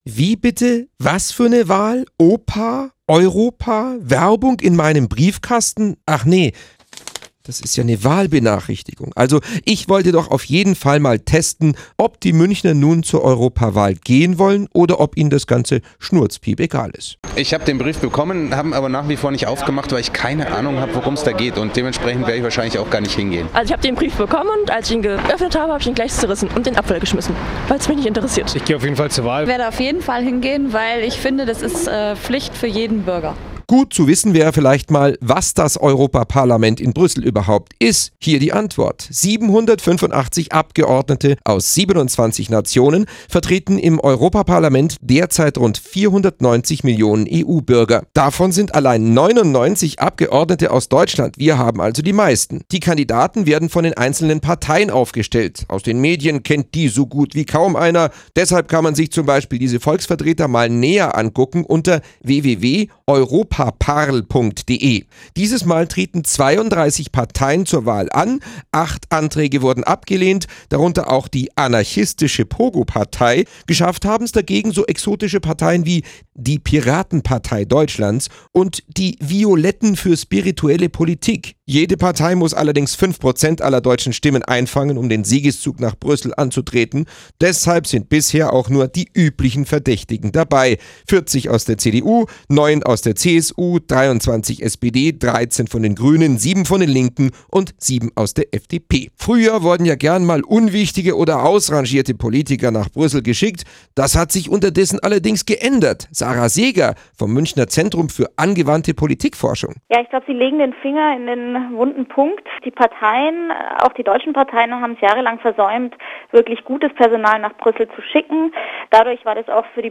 Audio-Beitrag zur Europawahl mit Statements